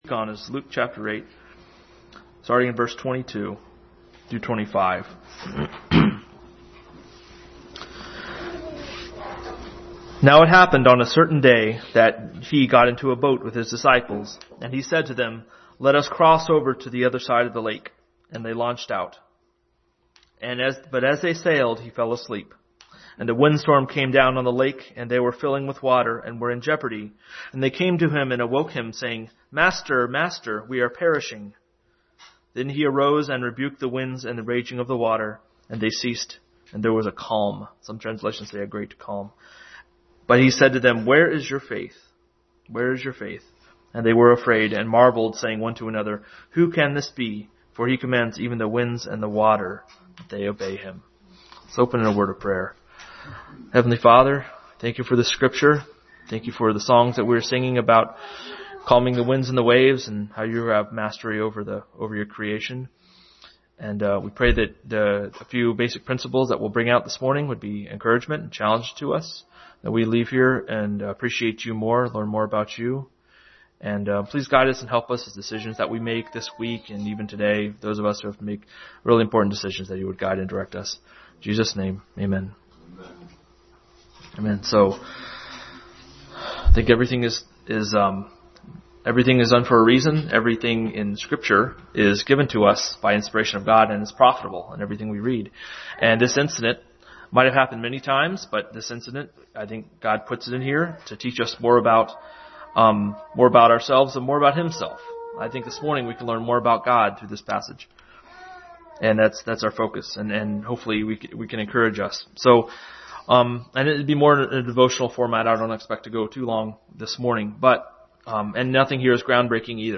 Luke 8:22-25 Passage: Luke 8:22-25 Service Type: Family Bible Hour